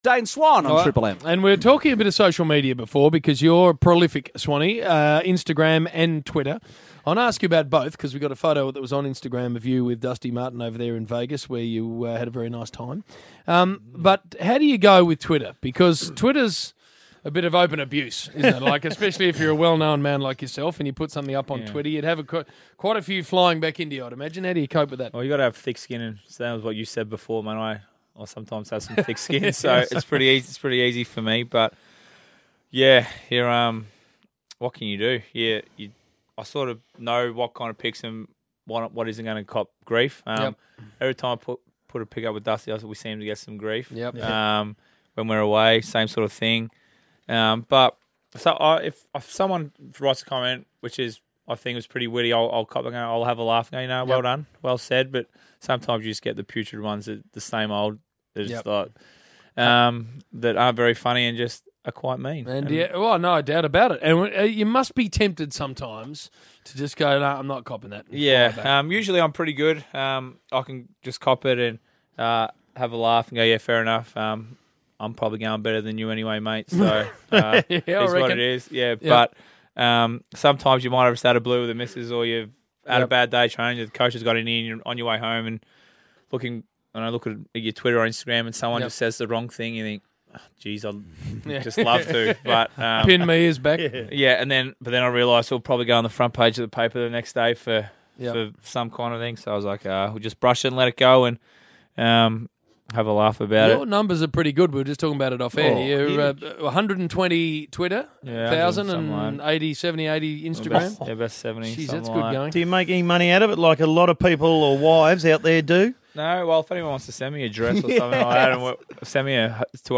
Listen to Collingwood champion Dane Swan chat with The Rush Hour's James Brayshaw and Bill Brownless on Triple M on Wednesday 25 March.